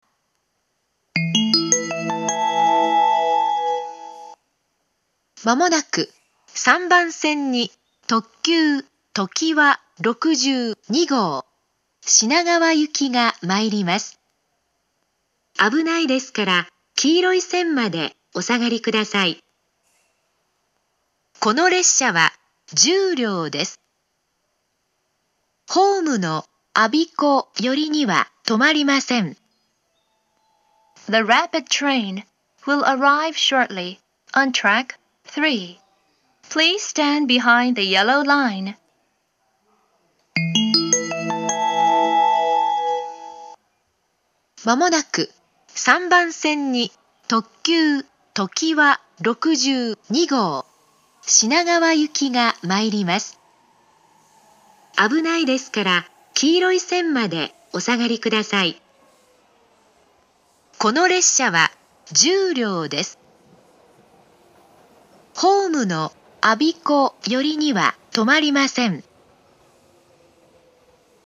３番線接近放送
３番線到着放送
発車メロディー(ＪＲＥ-ＩＫＳＴ-０１３-0１)
日中でも利用客が多いので、1コーラスは鳴りやすいです。